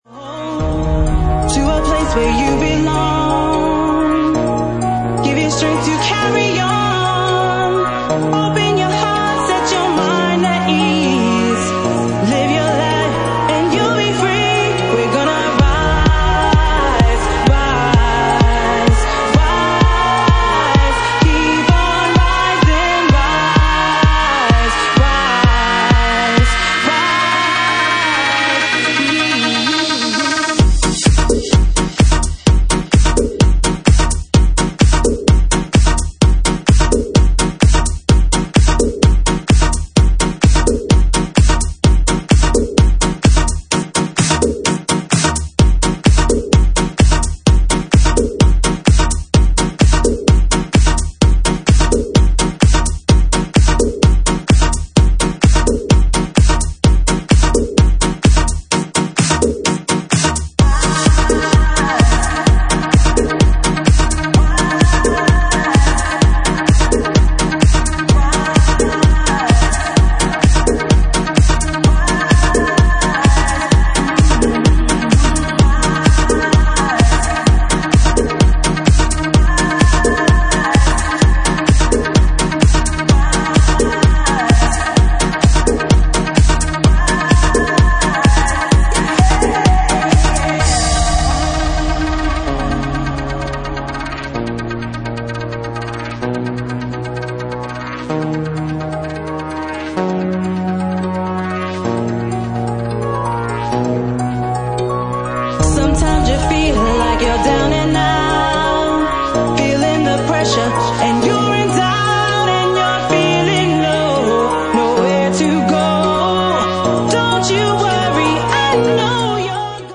Genre:Jacking House
Jacking House at 128 bpm